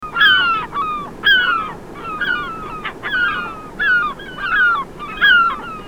Gabbiano reale
Larus argentatus
Un ripetuto e aspro ‘kiou’ è il richiamo più comune, ma possiede un’ampia varietà  di altri richiami.
Gabbiano-Reale-Larus.argentatus.mp3